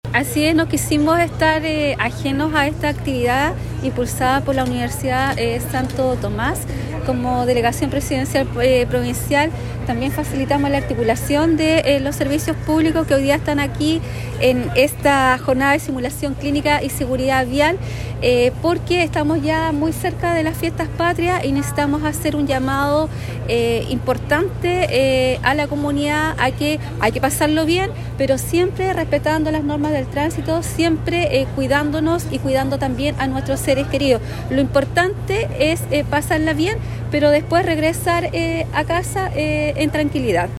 La Delegada Presidencial Provincial, Claudia Pailalef indicó que esta jornada responde al llamado que se debe realizar a la comunidad acerca de las medidas de prevención durante las próximas fiestas patrias.